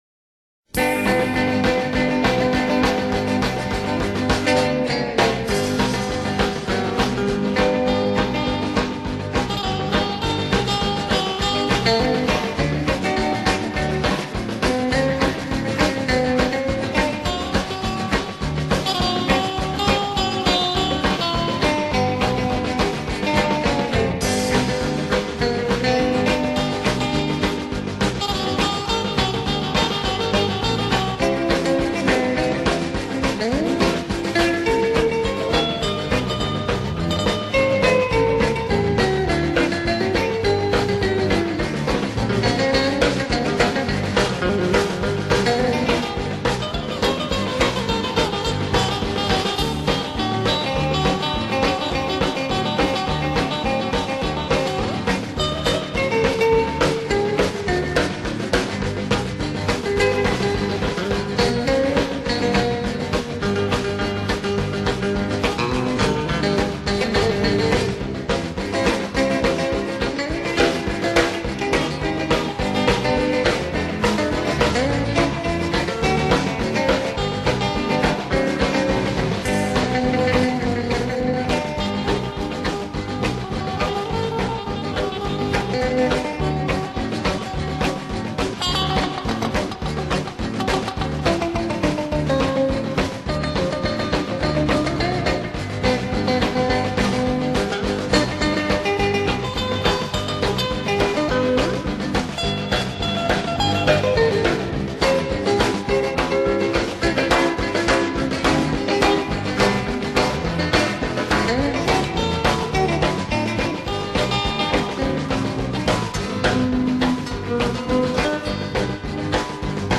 Ах, эти гитары, тем более, мелодия знакомая...